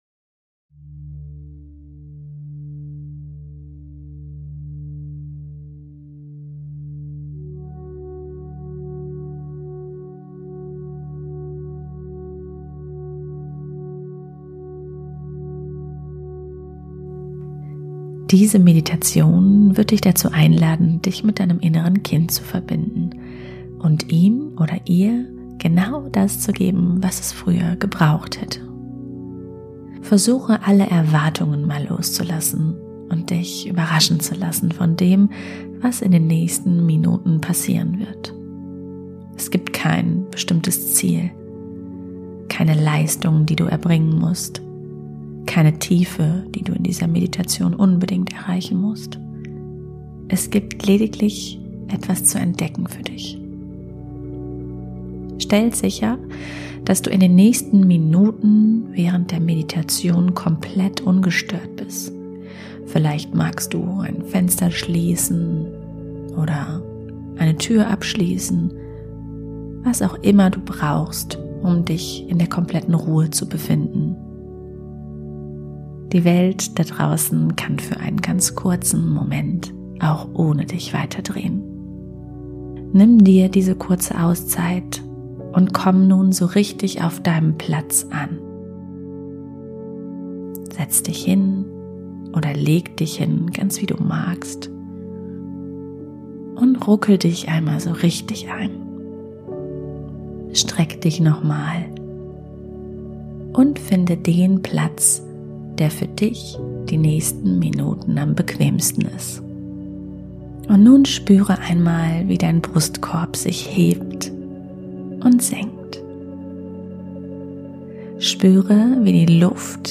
#16 Meditation: Inneres Kind halten und heilen ~ Emetophobie Podcast: Ausgebrochen angstfrei!